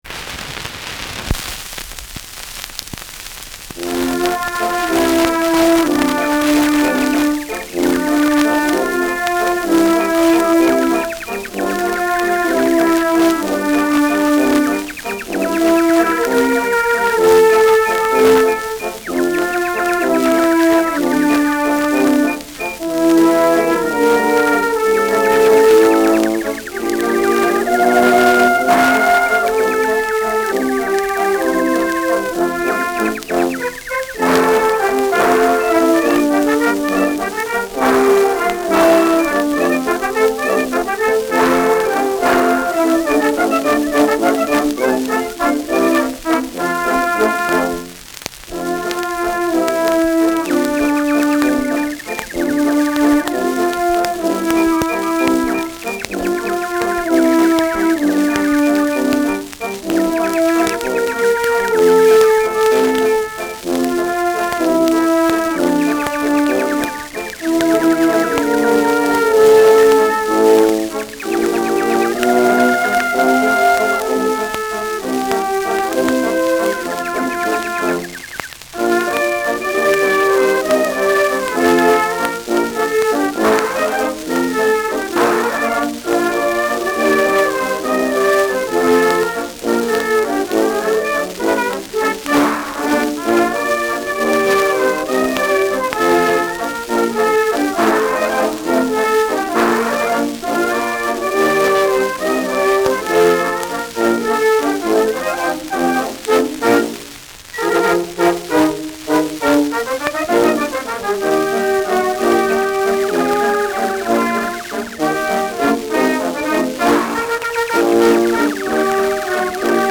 Schellackplatte
Mit Vogelgeräuschen.
[Berlin] (Aufnahmeort)